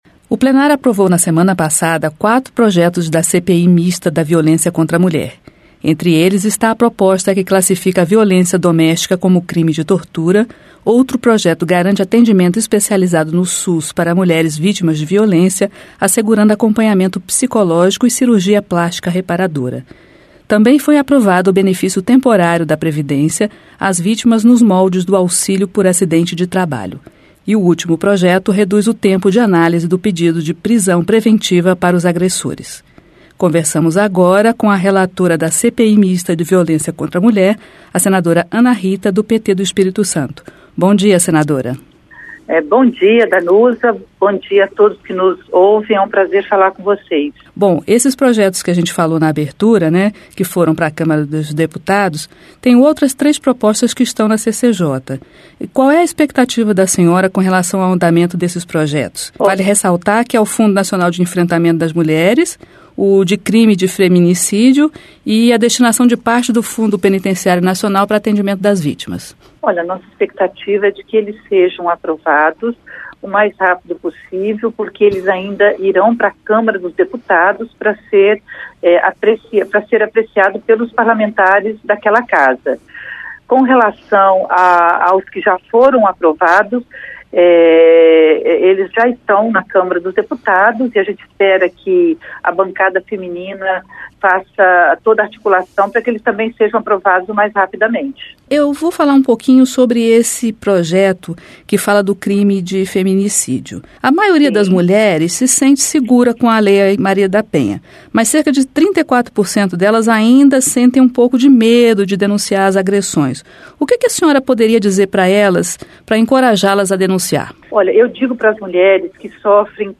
Entrevista: CPI mista da Violência contra a Mulher Entrevista com senadora Ana Rita (PT-ES), relatora da CPMI da Violência contra a Mulher.